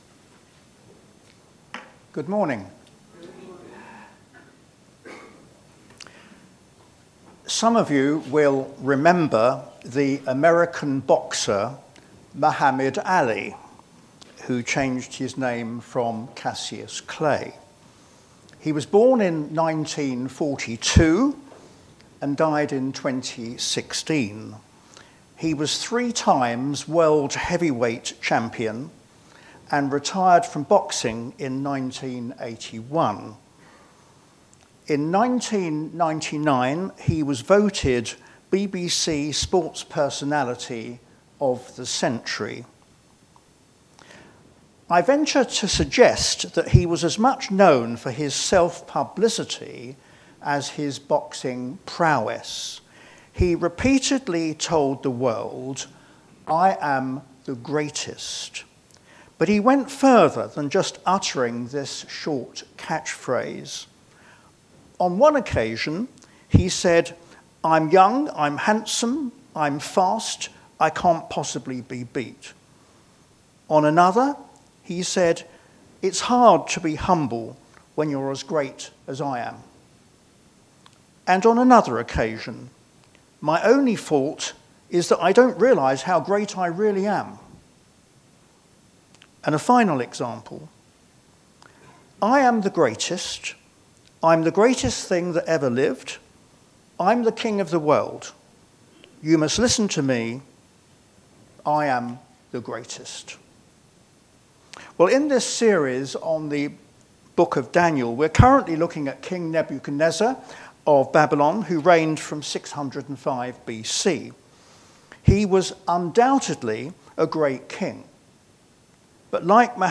Media for a.m. Service on Sun 06th Jul 2025 10:30
Passage: Daniel 4: 28-37 Series: Daniel - Faith in a foreign culture Theme: Sermon